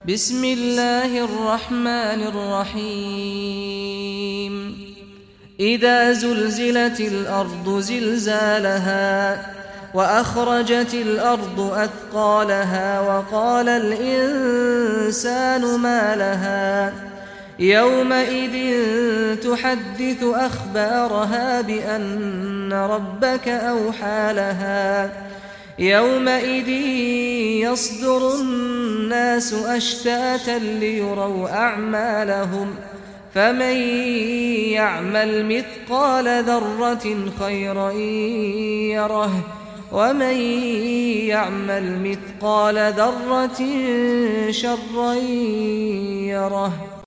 Surah Az-Zalzalah Recitation by Sheikh Saad Ghamdi
Surah Az-Zalzalah, listen or play online mp3 tilawat / recitation in Arabic in the beautiful voice of Imam Sheikh Saad al Ghamdi.